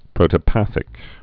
(prōtə-păthĭk)